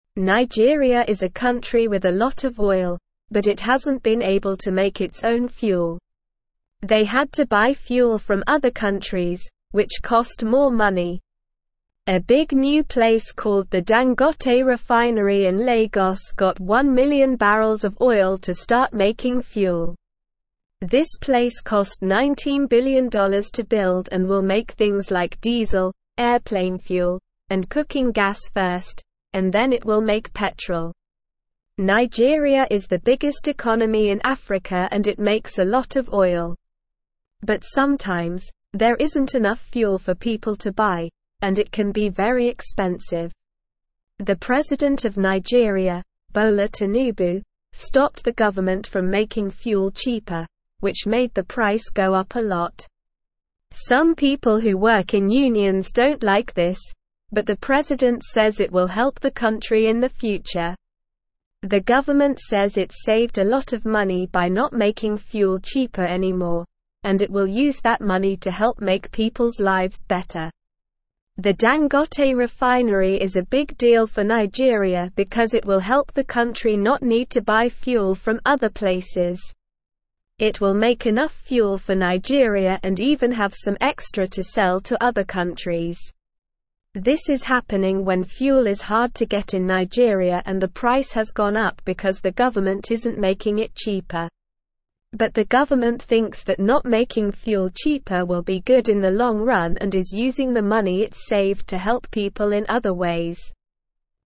Slow
English-Newsroom-Lower-Intermediate-SLOW-Reading-Nigeria-Makes-Its-Own-Gas-to-Save-Money.mp3